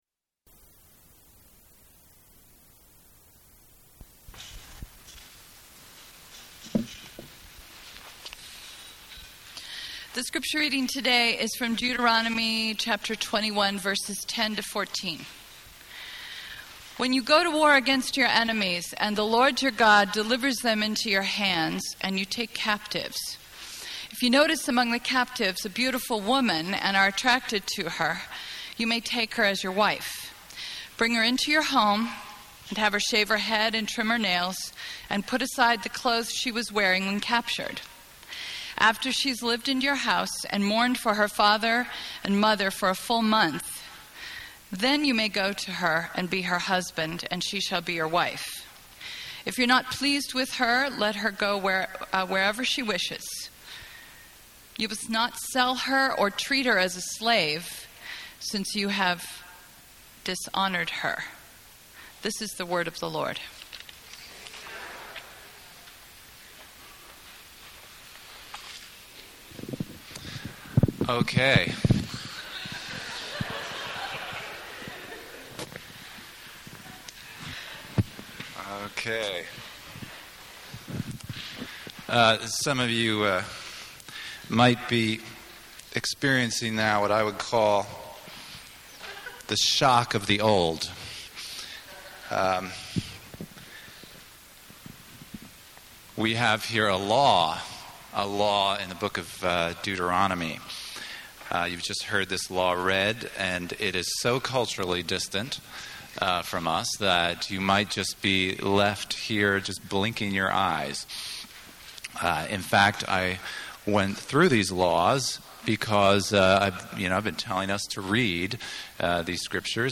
You could hear a pin drop after reading this passage, what my wife calls a pew-gripper.